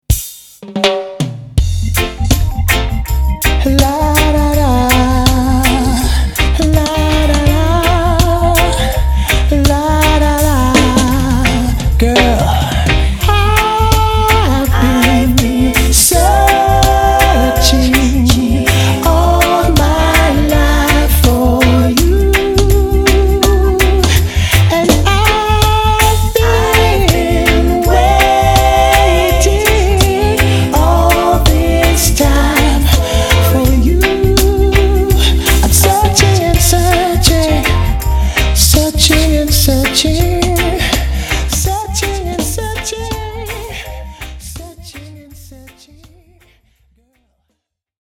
Reggae singer
soul massaging music